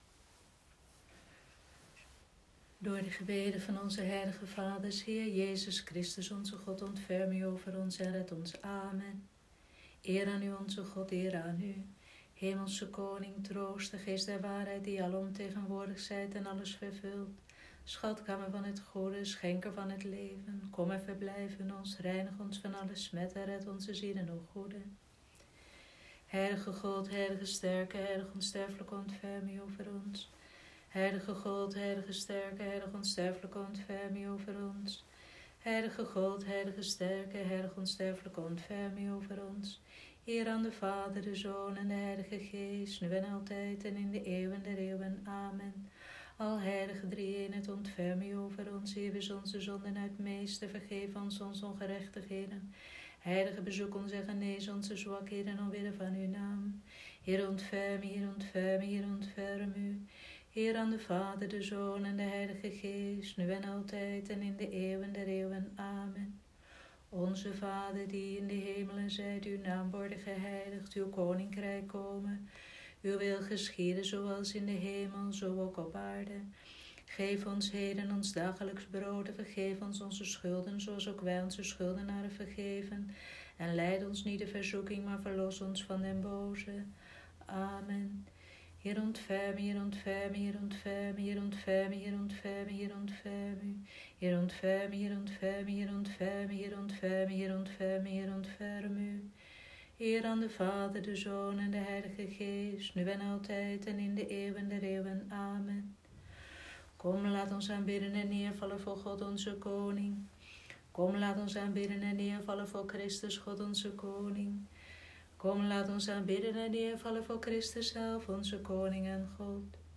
Vespers van Donderdagavond, 2 April 2020